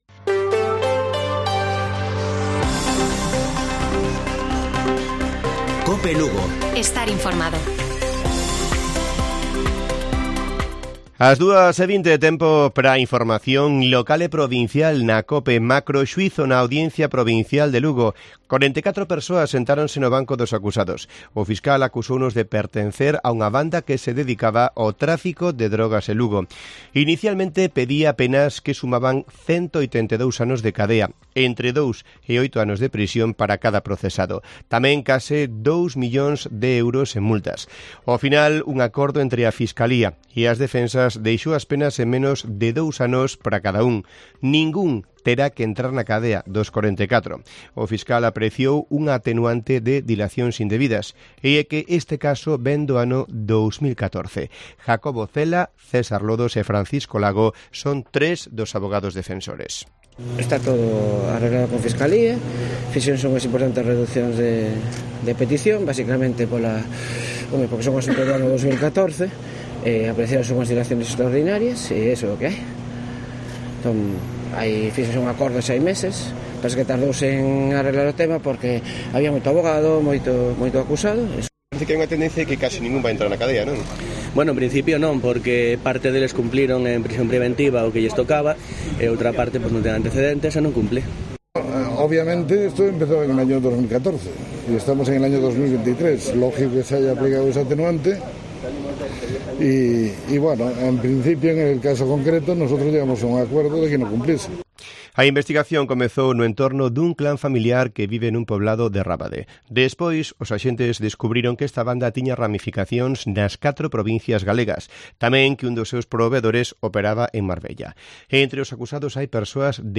Informativo Mediodía de Cope Lugo. 27 de julio. 14:20 horas